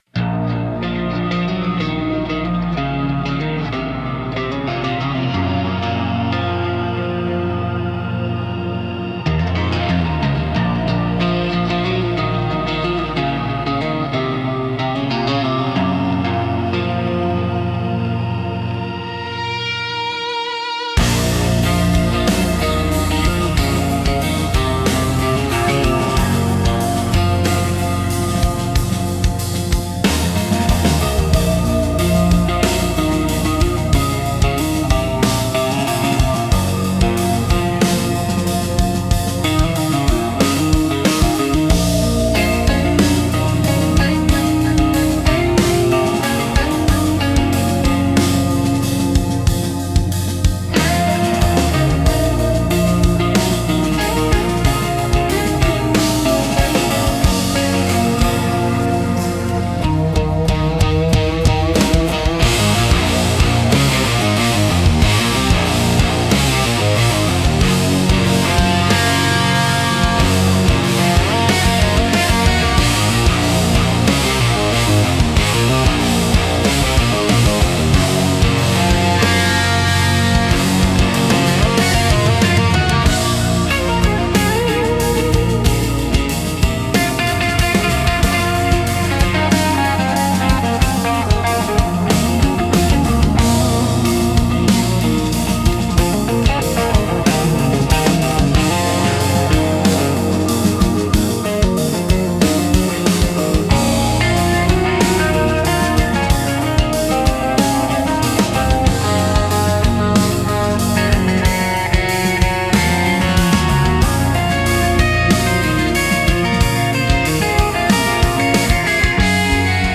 Instrumental - Empty Passenger Seat